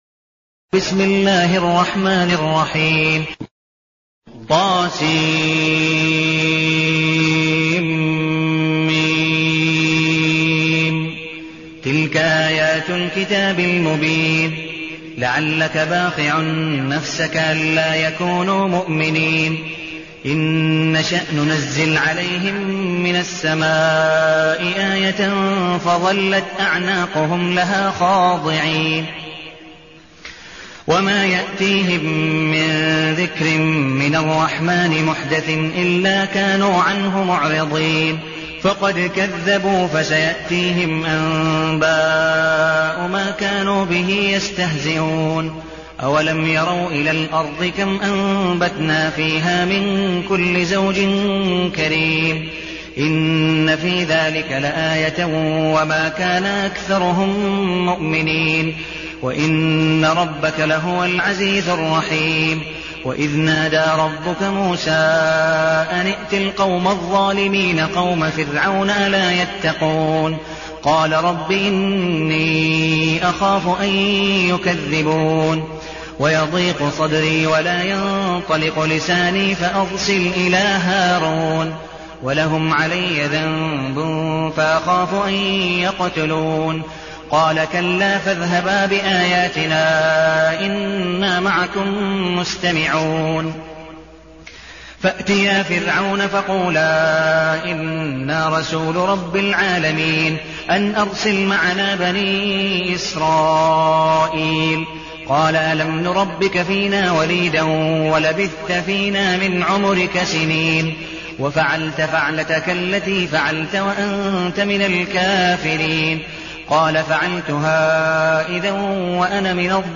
المكان: المسجد النبوي الشيخ: عبدالودود بن مقبول حنيف عبدالودود بن مقبول حنيف الشعراء